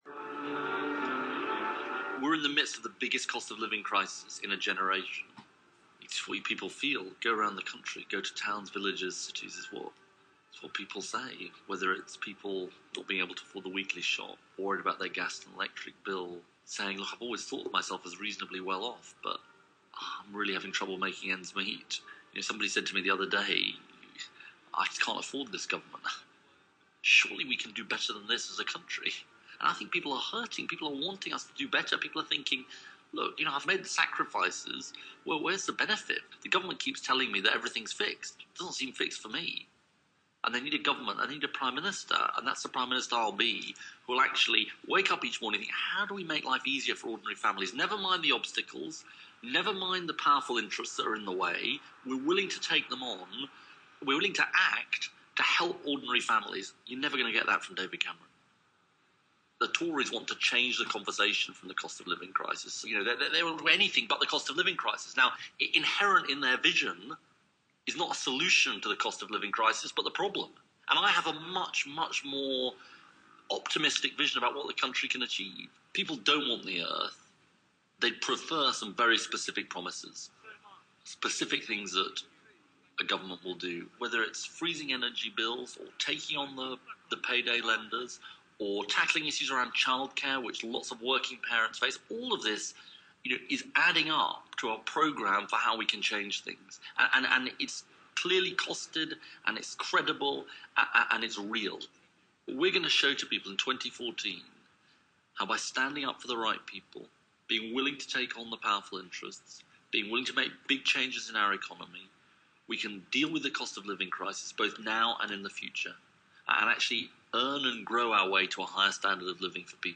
The Labour leader gives his new year message for 2014